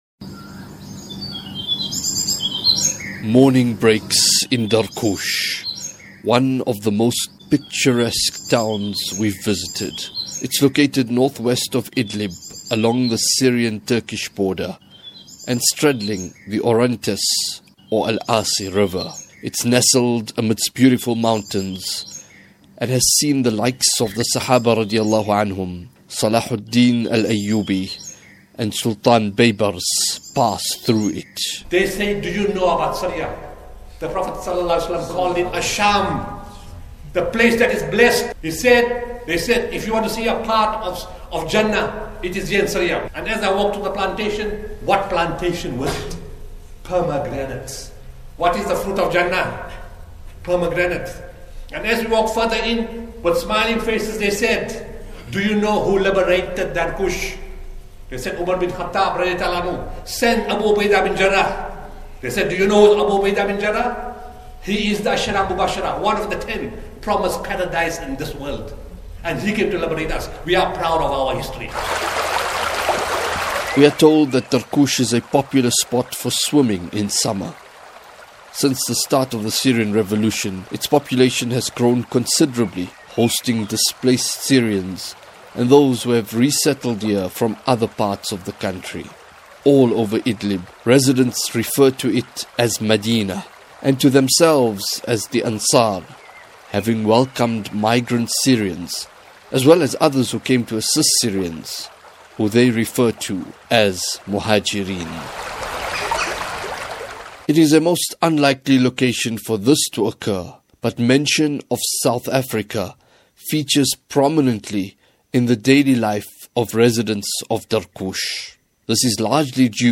Documentary – Episode 04: Al- Rahmah Hospital A South African haven of mercy in Syria